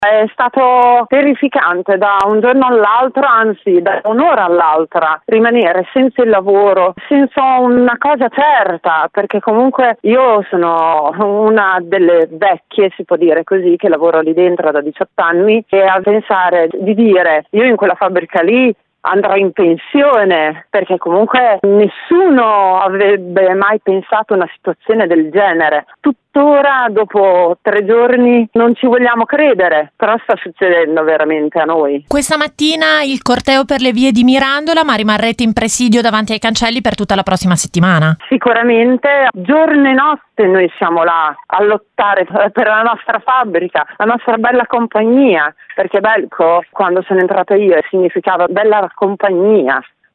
Si è svolto questa mattina per le vie di Mirandola il corteo delle lavoratrici e dei lavoratori della Mozarc Bellco, che, insieme a  sindacalisti e cittadini e ai lavoratori di tutte le altre imprese del distretto hanno sfilato sotto lo slogan “Giù le mani dal biomedicale!”.